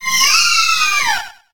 Cri de Charmilly dans Pokémon HOME.